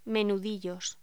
Locución: Menudillos
voz